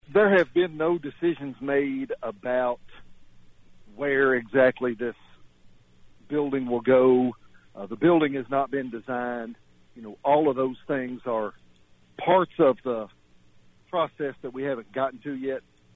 The judicial center project is still in the early planning phases, and Judge Alexander said they have not made a final decision on location